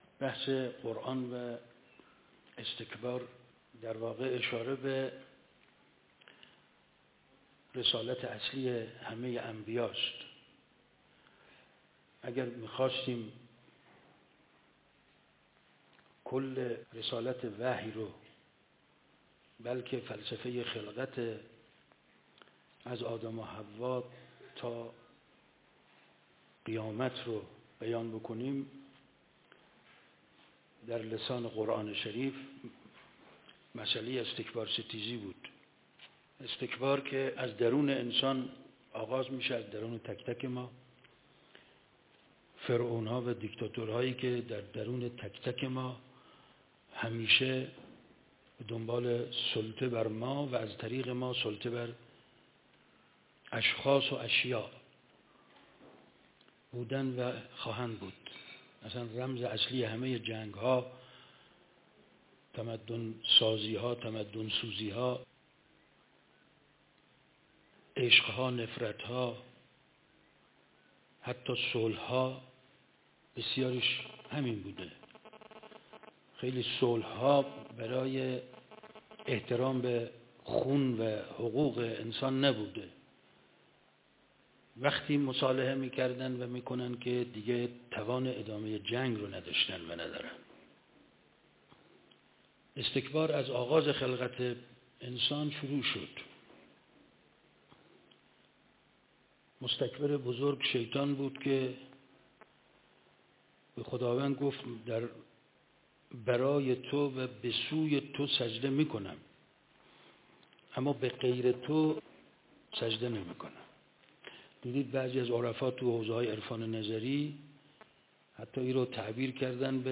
دانشگاه تربیت مدرس قرآن _نشست ('کفر' و نسبت آن با استکبار؛ 'کفر ستیزی' یا 'کافرستیزی' ؟ )_۱۳۹۷